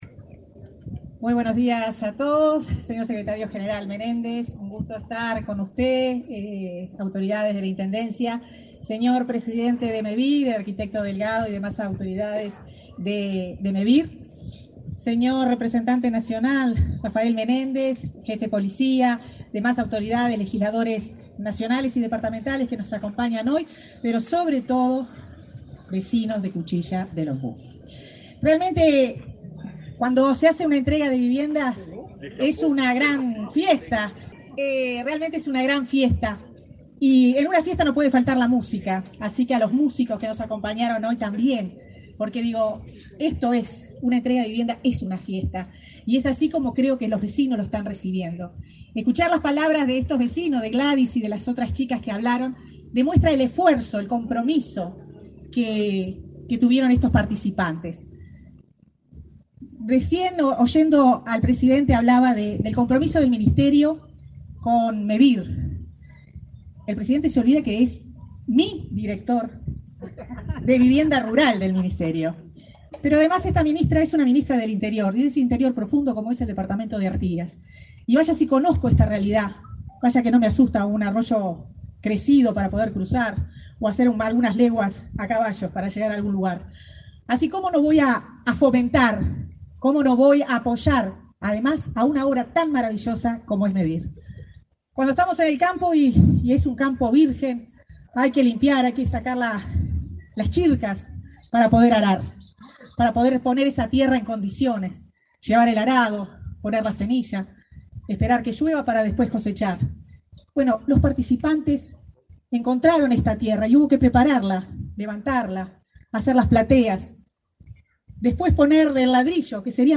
Conferencia de prensa por la entrega de casas de Mevir en Tacuarembó
Participaron del evento el presidente de Mevir, Juan Pablo Delgado, y la ministra de Vivienda, Irene Moreira.